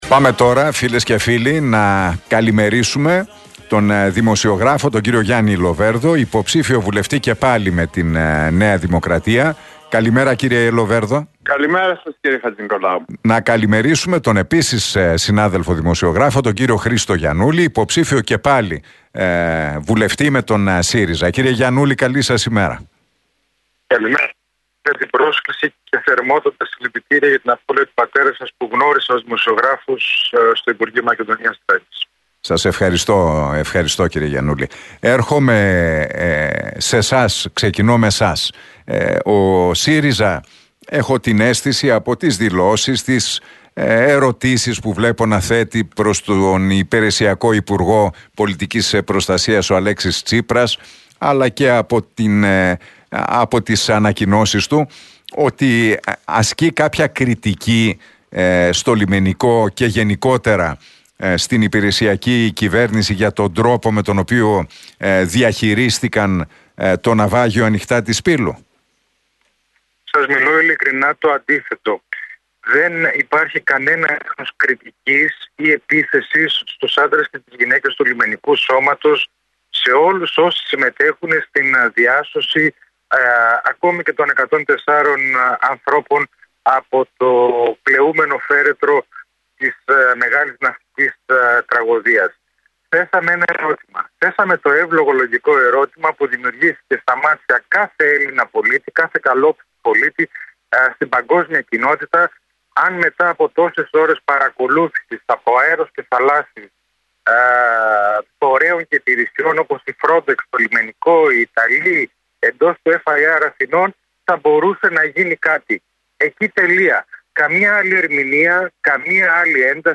Τα ξίφη τους διασταύρωσαν στον αέρα του Realfm 97,8 σε ένα ραδιοφωνικό debate, στην εκπομπή του Νίκου Χατζηνικολάου ο υποψήφιος με τη ΝΔ, Γιάννης Λοβέρδος και ο υποψήφιος με τον ΣΥΡΙΖΑ, Χρήστος Γιαννούλης.